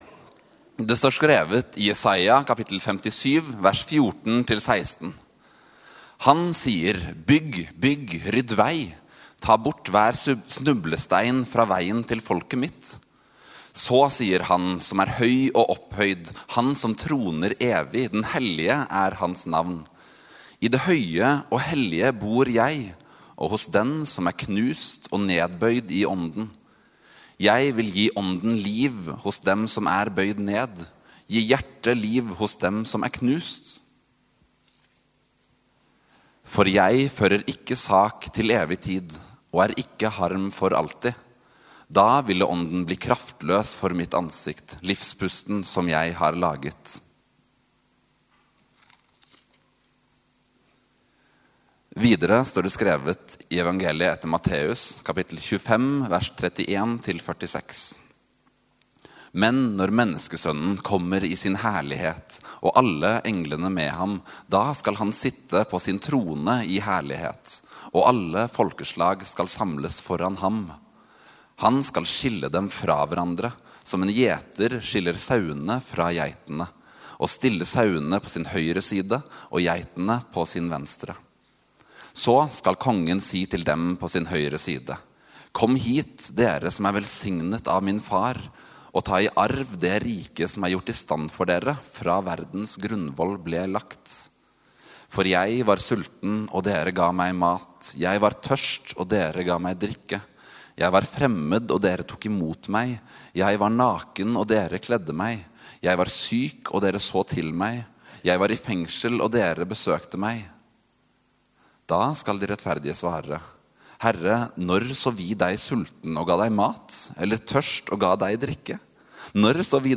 Gudstjeneste kveld 26. november 23, Immanuel-domsøndag | Storsalen